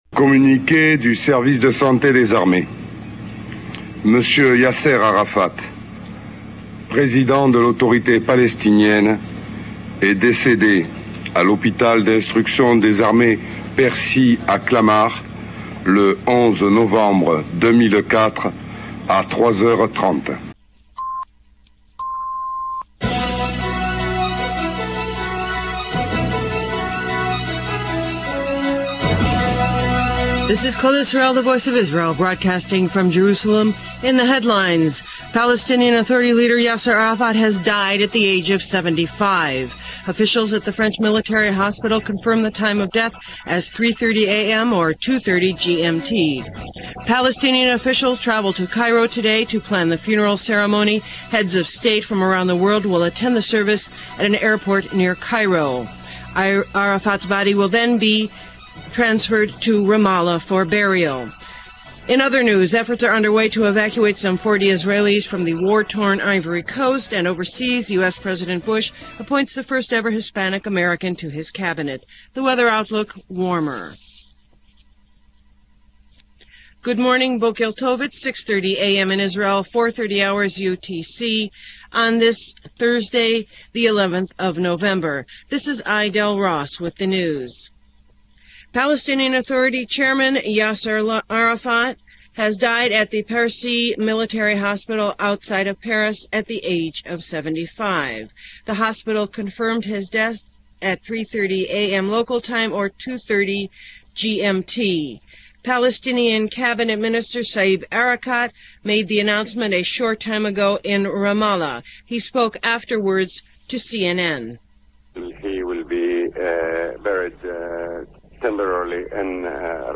As has always been there custom, it was straight-forward reporting with no spin or speculation; just the facts.